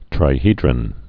(trī-hēdrən)